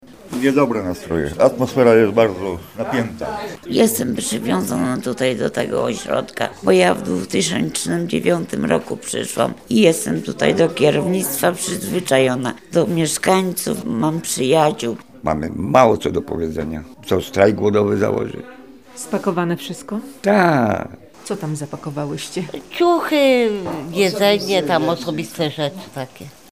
Jestem tutaj do kierownictwa przyzwyczajona, do mieszkańców, mam przyjaciół – mówiła jedna z pań.